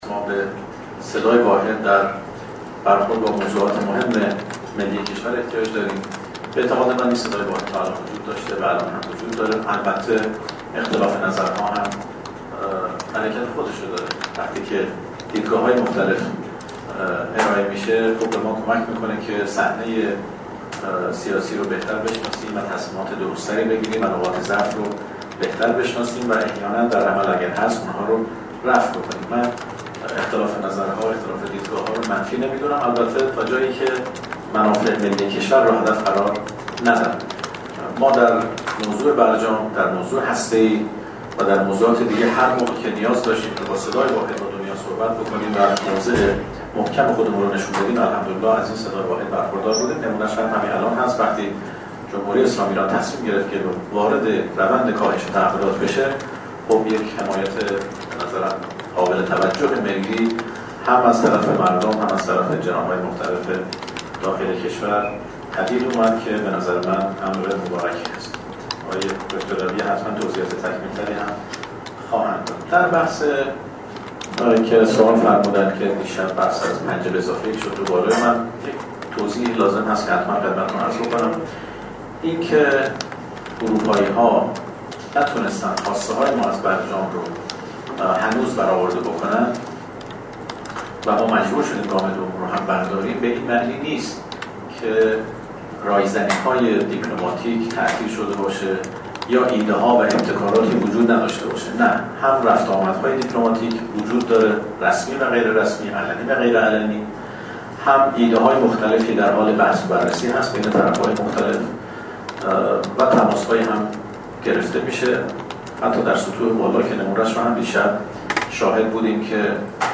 به گزارش خبرنگار سیاسی خبرگزاری رسا، سید عباس عراقچی معاون سیاسی وزارت خارجه صبح امروز همزمان با پایان یافتن مهلت ۶۰ روزه ایران به کشورهای اروپایی، در نشست خبری اعلام تصمیمات جدید کشورمان درباره کاهش تعهدات برجامی با اشاره به اینکه کاهش تعهدات برجامی از سوی دولت در راستای حفظ برجام بوده نه در راستای از بین بردن برجام گفت: حرکت ما به گونه ای است که فرصت برای تعامل وجود داشته باشد.